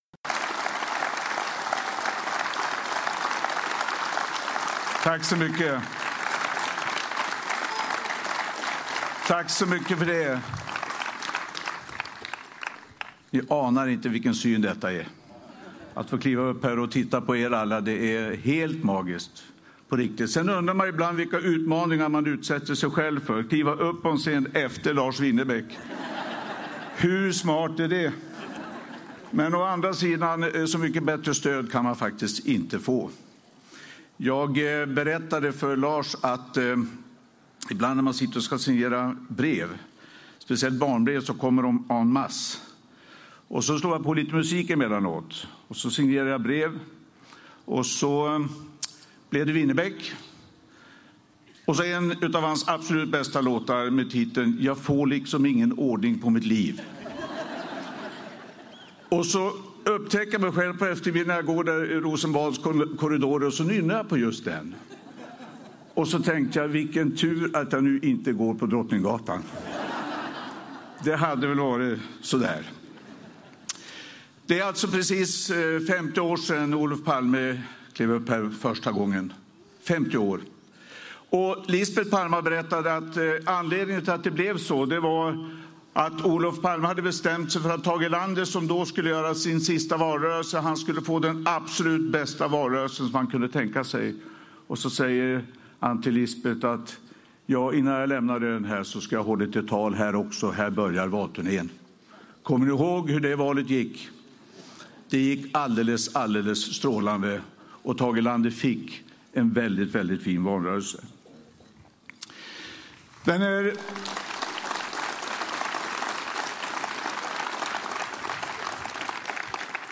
Stefan Löven talar i Almedalen 2018.
Statsminister Stefan Löven talar under Almedals veckan.